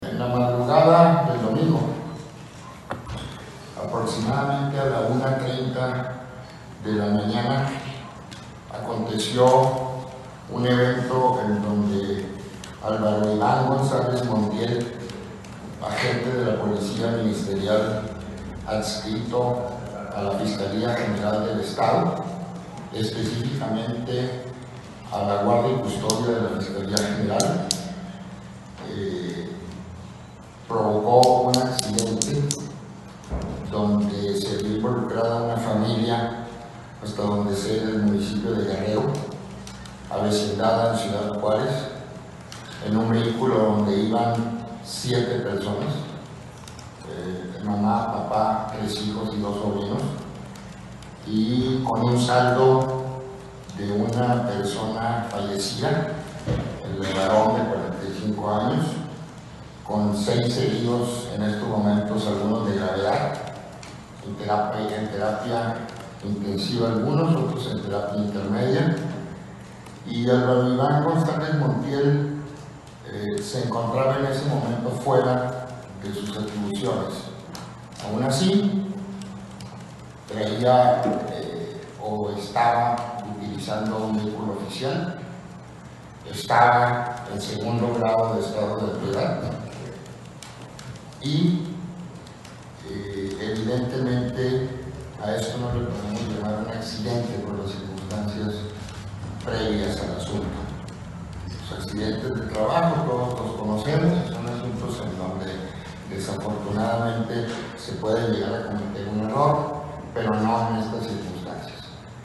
AUDIO: CÉSAR JAÚREGUI MORENO, FISCAL GENERAL DEL ESTADO (FGE)